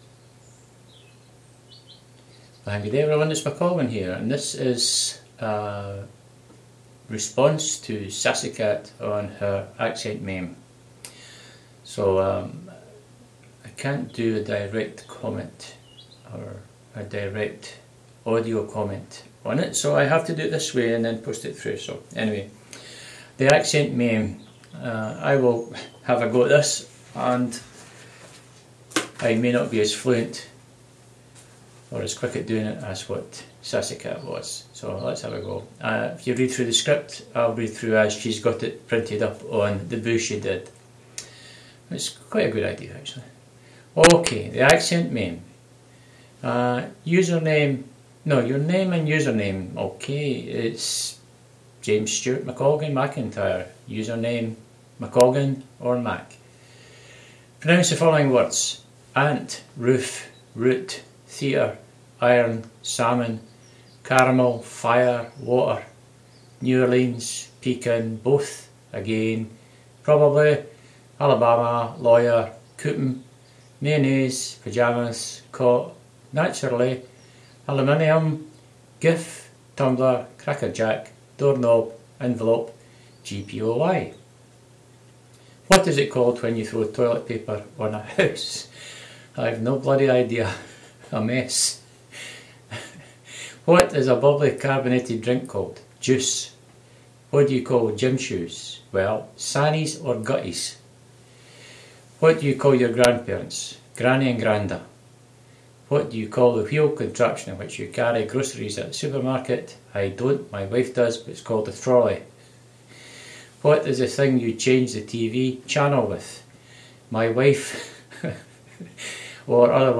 International accents test.